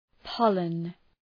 Προφορά
{‘pɒlən}